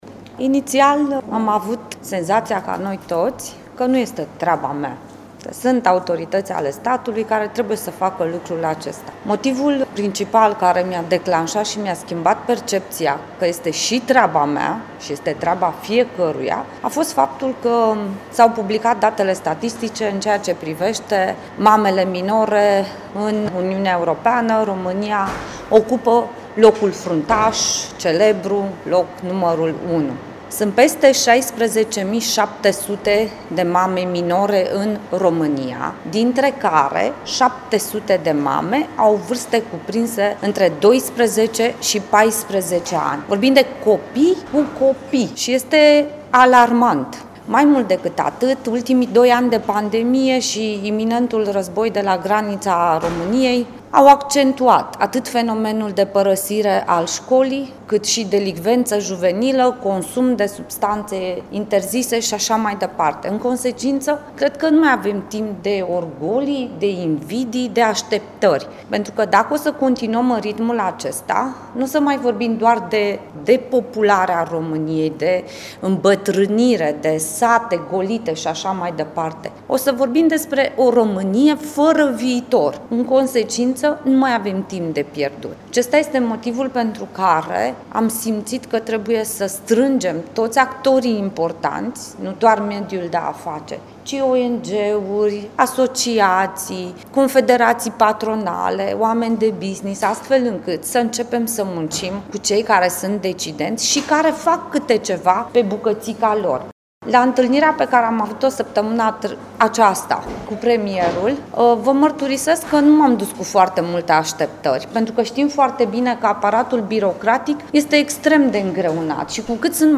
Abandonul școlar – o criză extrem de gravă cu care se confruntă România, a fost tema dezbaterii organizată ieri, la Botoșani, de Confederația Națională pentru Antreprenoriat Feminin (CONAF), în cadrul proiectului național „Pactul pentru Tineri”, în parteneriat cu Ministerul Educației, Ministerul Familiei, Tineretului și Egalității de Șanse, Ministerul Muncii și Solidarității Sociale, autoritățile locale, Banca Mondială și UNICEF.